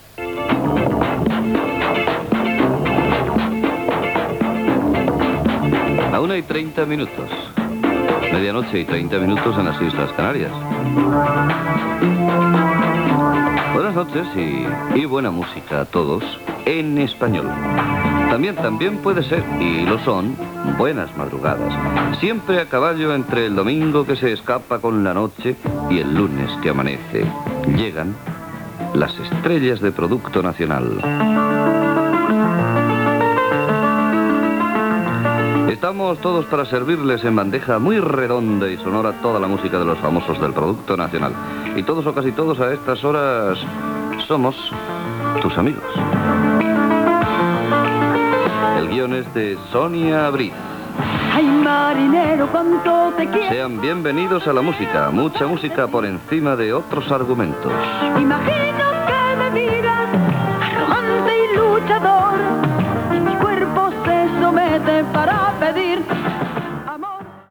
Hora, presentació del programa, nom de la guionista, tema musical
Musical